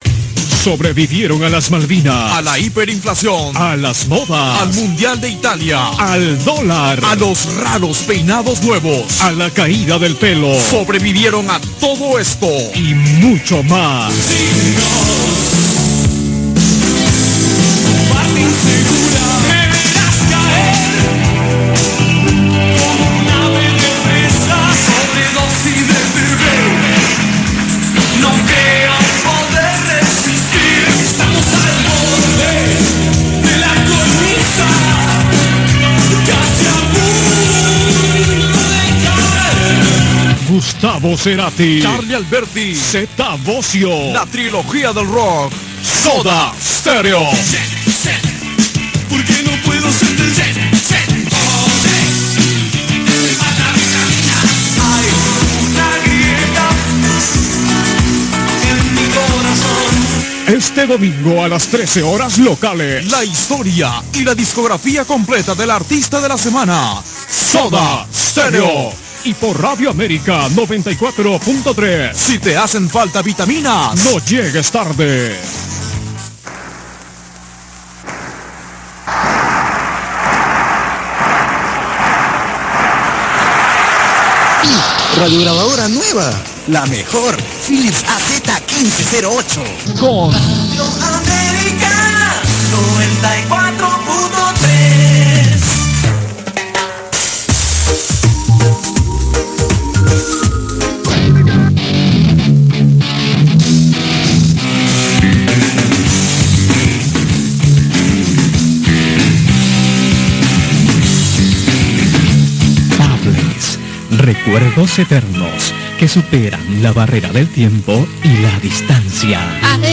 El audio de radio América que publicó  tiene un sonido horrible (lamentablemente), así que decidí *corregirlo *y subirlo por este medio, ya que puedo tener problemas con Copyright si lo subo a mi canal de YouTube.